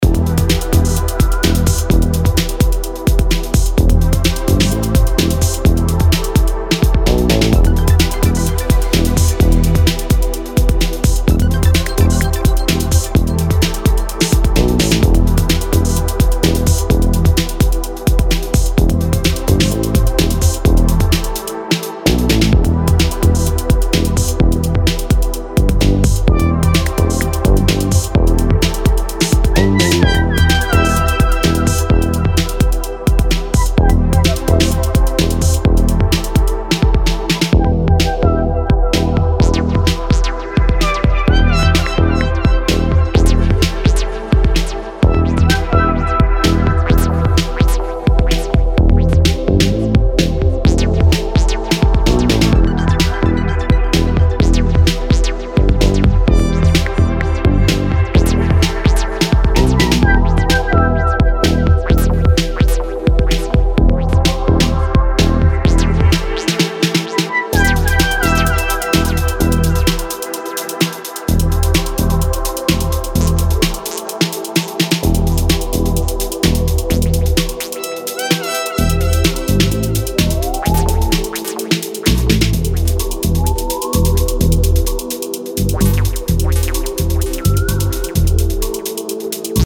subaquatic trip on the Detroitish side of electro
fuel for the dancefloor and food for the brain